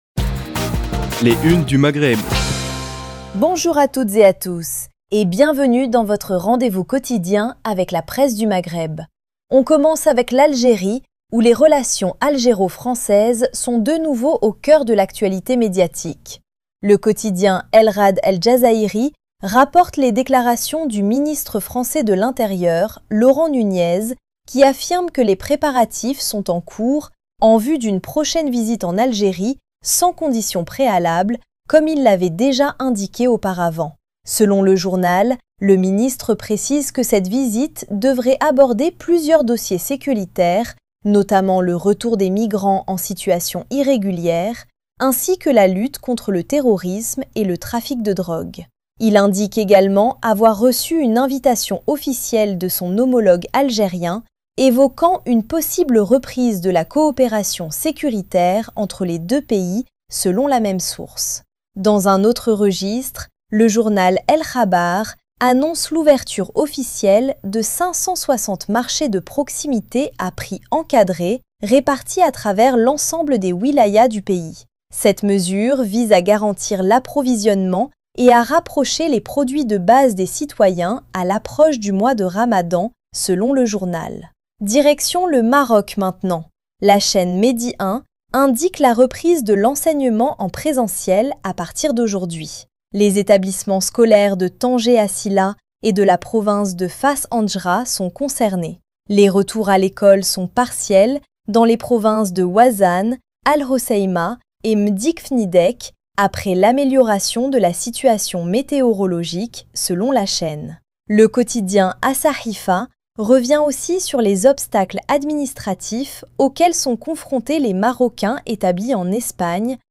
Revue de presse des médias du Maghreb